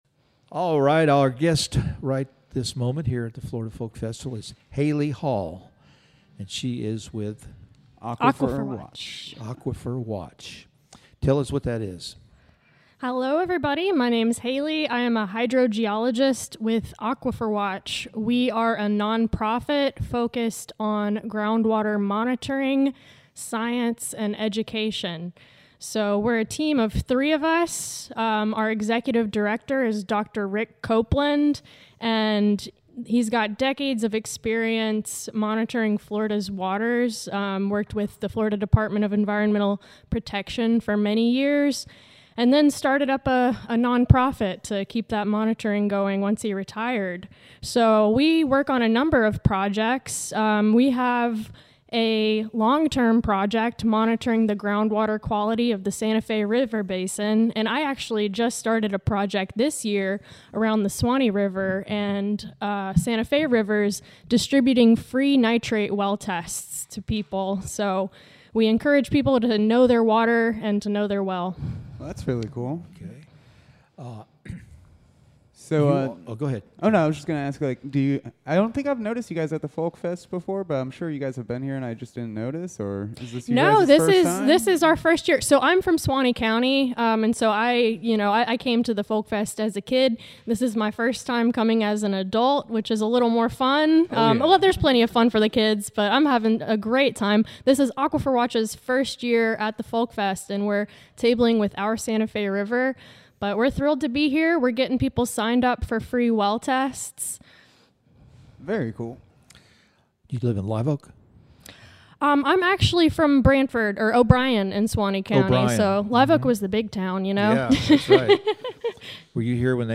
Florida Folk Festival Interview